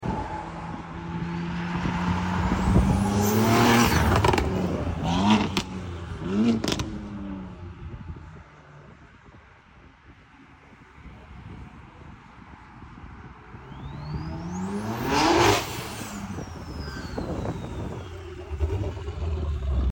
For the people PM'Ing me wondering this is a modification of the factory turbo compressor housing to make it have the single turbo sound.